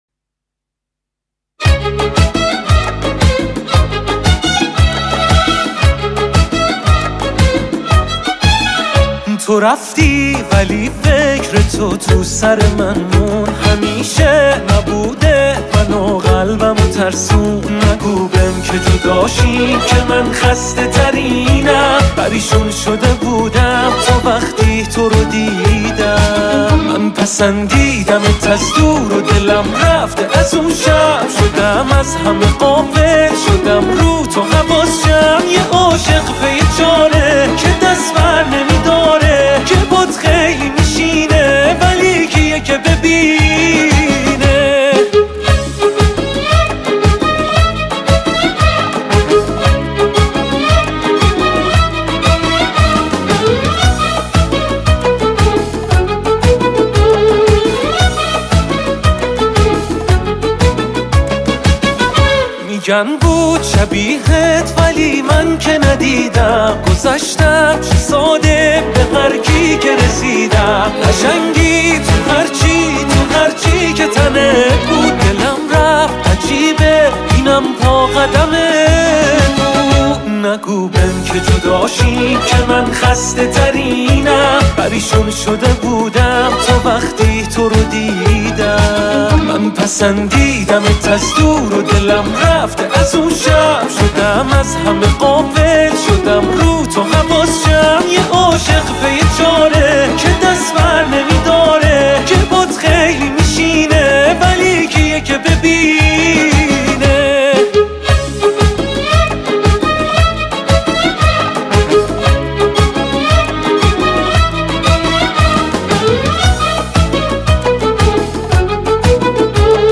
شاد
شاد فارسی